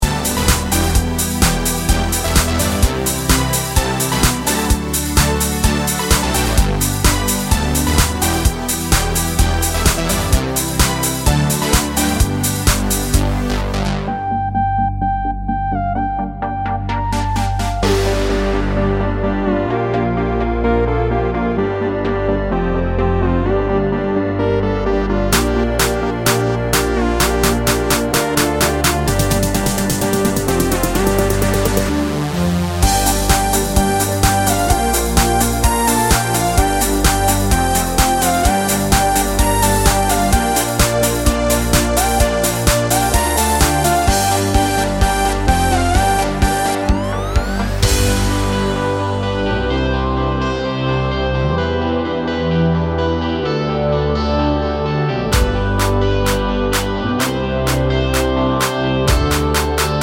no Backing Vocals Comedy/Novelty 3:09 Buy £1.50